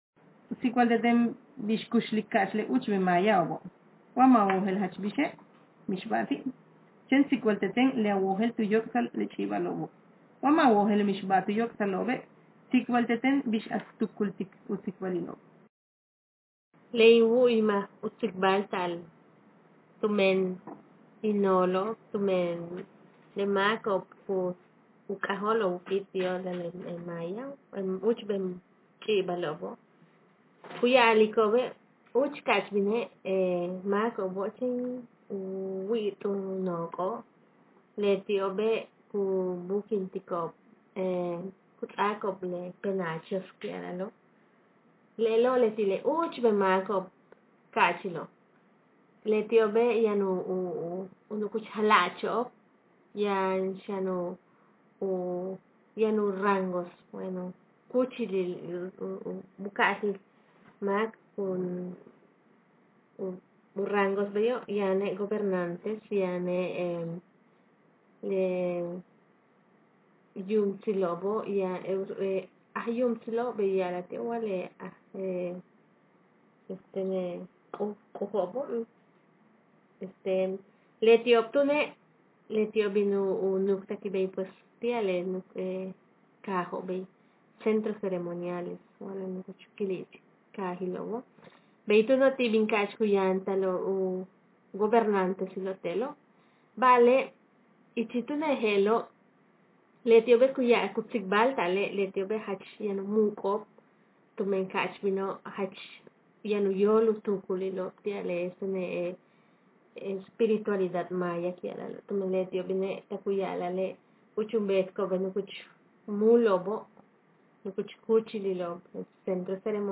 Speaker sex f Text genre personal narrative